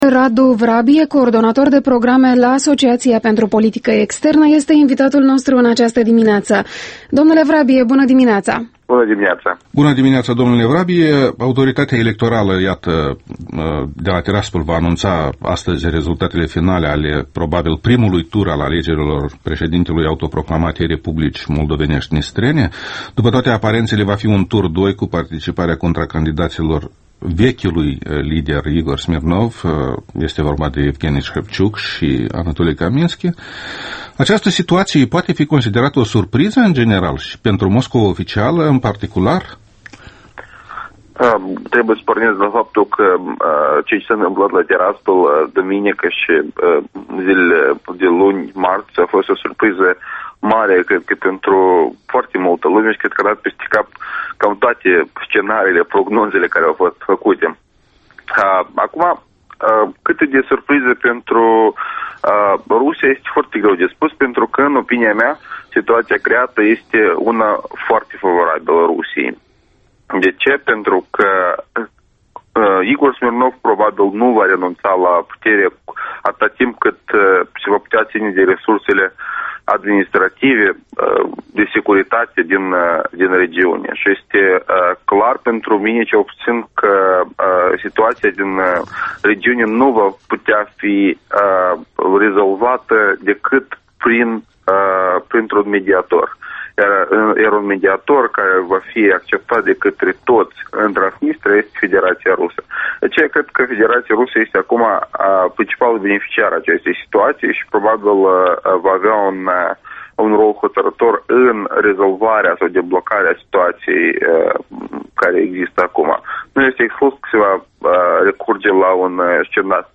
Embed Interviul dimineții la Europa Liberă